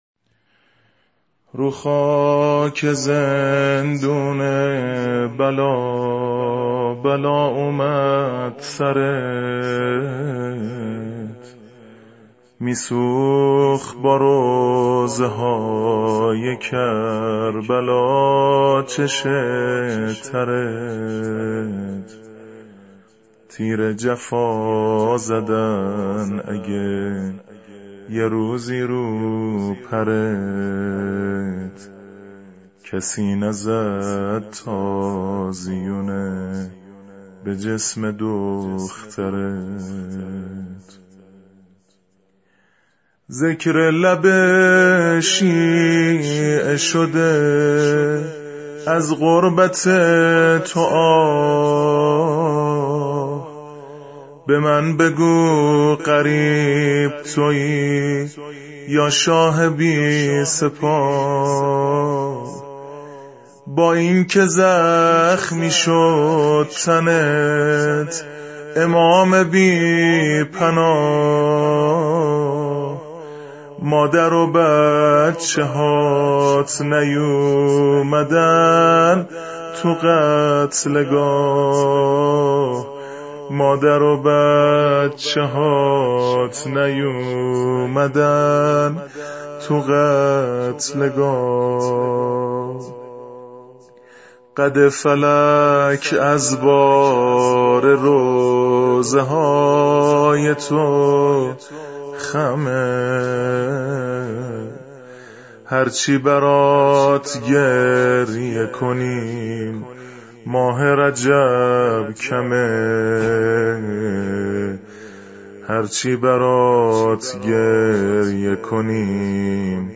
شور ، زمزمه